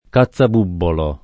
cazzabubbolo [ ka ZZ ab 2 bbolo ] s. m.